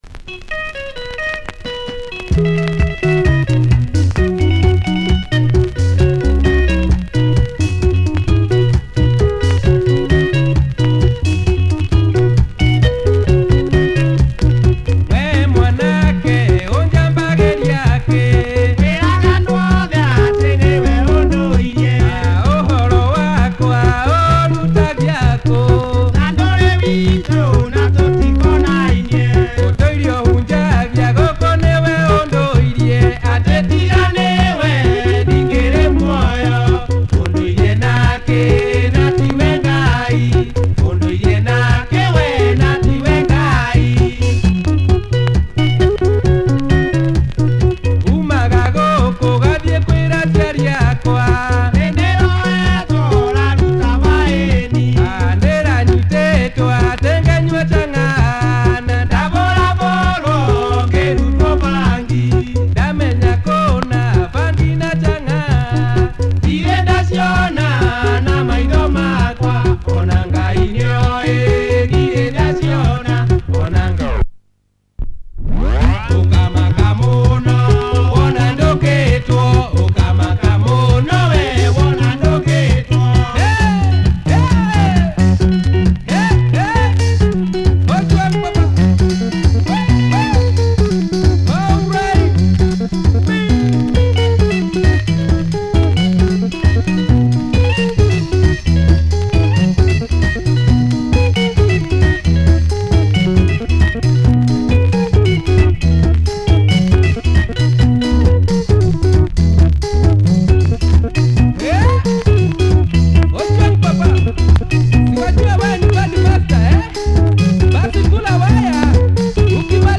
Super breakdown!